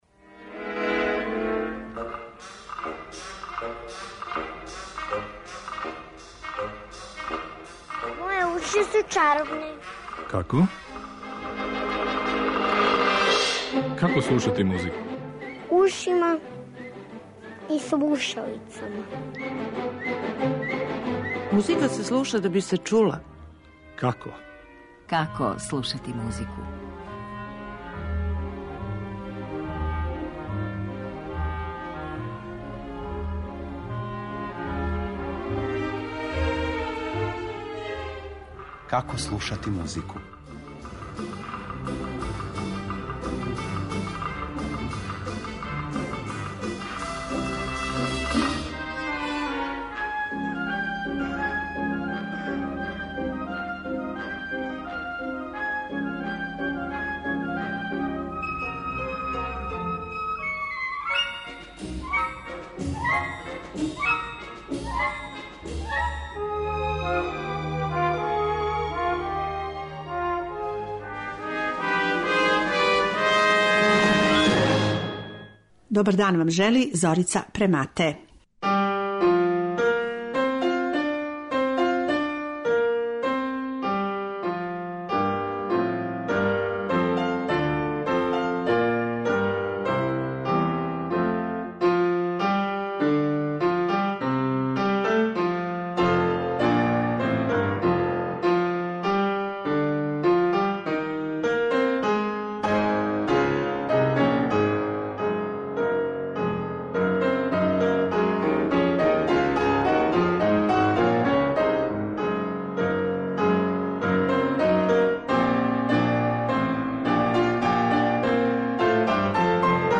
Највише пажње поклонићемо руској традицији и начинима на које је обогатила уметничку музику делима Глинке, Мусоргског, Бородина и Чајковског, а чућете и примере са осталих европских м